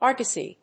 /ˈɑrgʌsi(米国英語), ˈɑ:rgʌsi:(英国英語)/
フリガナアーガシー